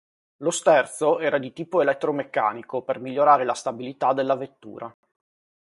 vet‧tù‧ra
Pronounced as (IPA)
/vetˈtu.ra/